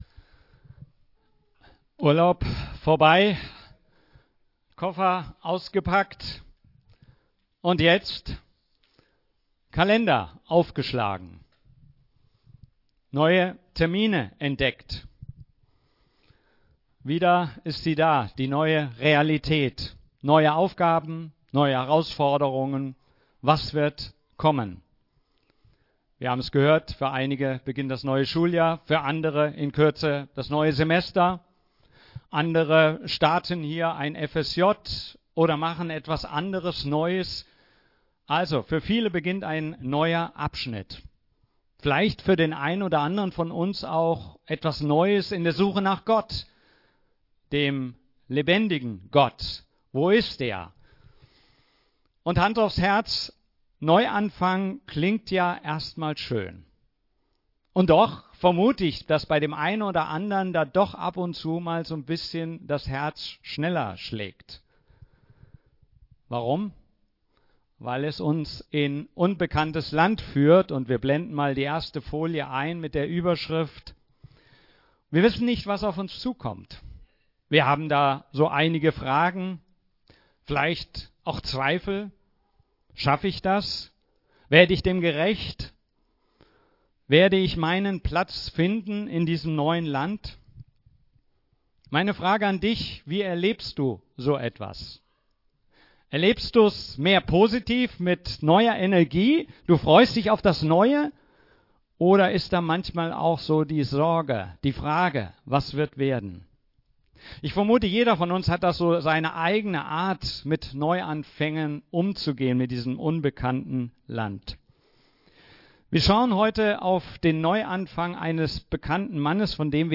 Predigten FeG Schwerin Podcast